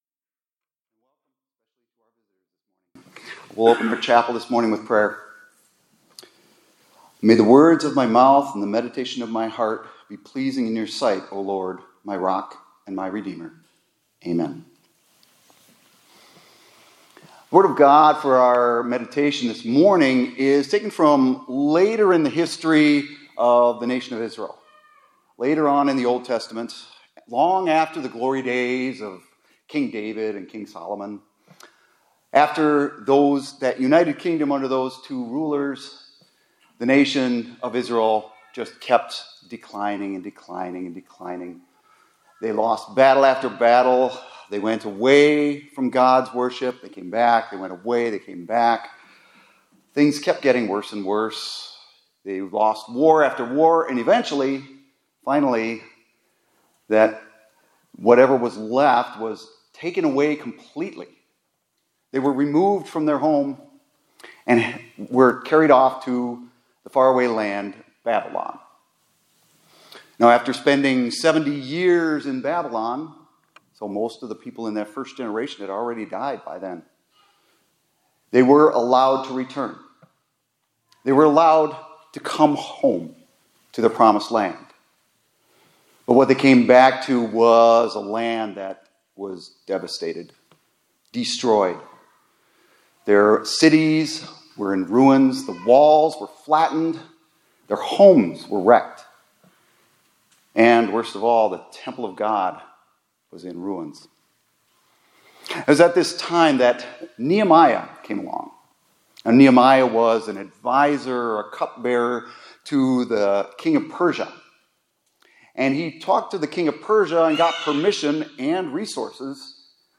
2025-03-14 ILC Chapel — The Christian Life is Both Offense and Defense